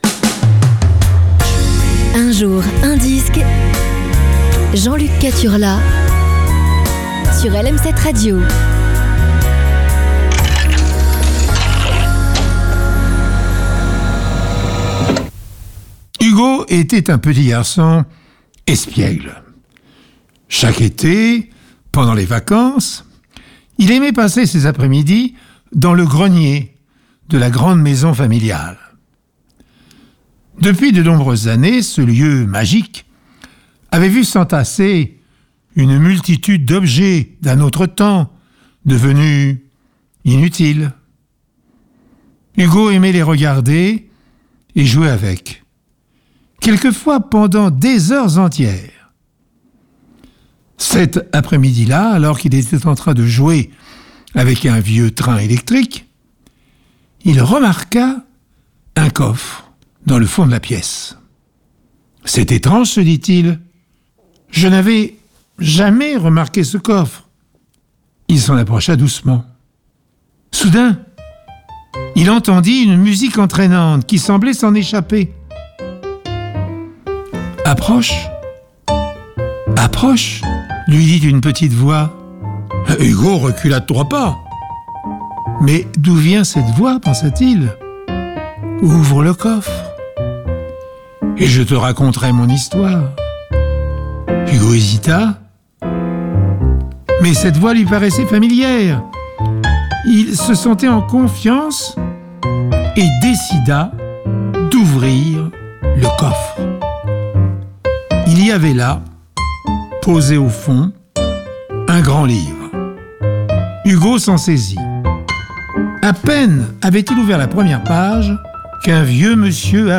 racontée par Pierre Bellemare